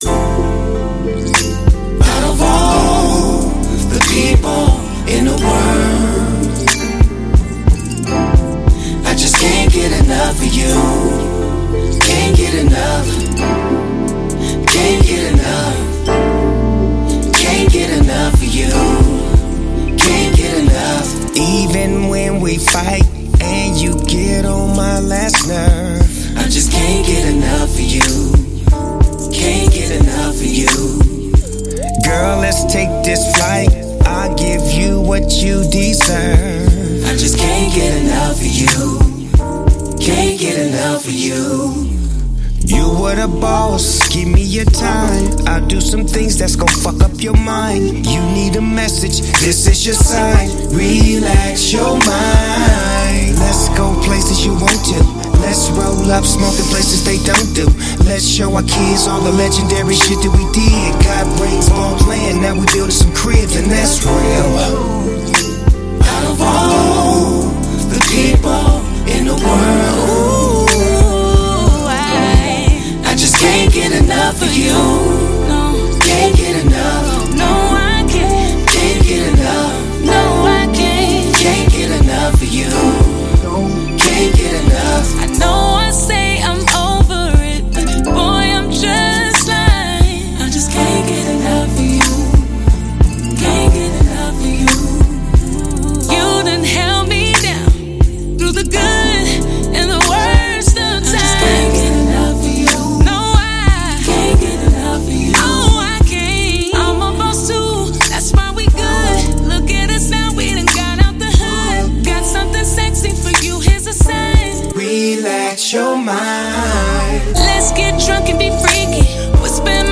classic West Coast sound with smooth beats and cool lyrics
deep voice and relaxed flow
Fans of old-school hip-hop will love this album.